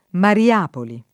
vai all'elenco alfabetico delle voci ingrandisci il carattere 100% rimpicciolisci il carattere stampa invia tramite posta elettronica codividi su Facebook Mariapoli [ mari- # poli ] top. f. — nome di sedi stabili, o anche di sedi di convegno, del Movimento dei Focolari